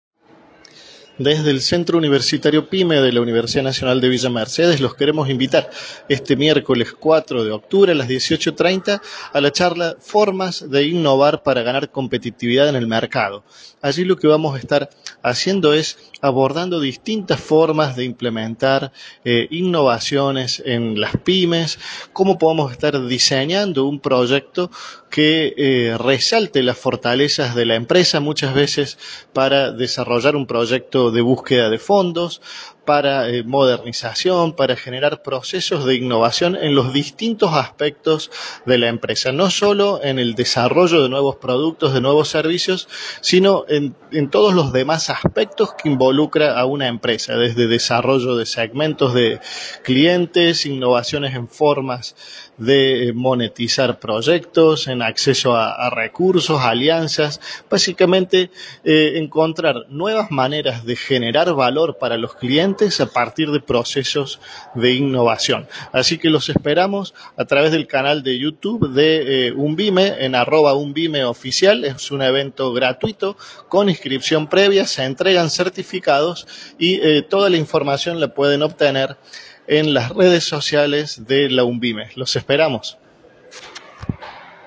Formulario de Inscripción A través de un audio, el prestigioso expositor invita a participar de esta capacitación.